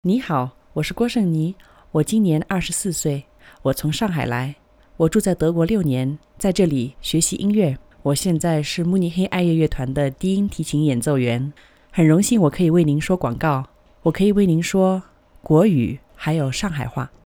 Chinesische Sprecherin, Muttersprachlerin. Mandarin und Shanghai Dialekt.
female chines voice over artist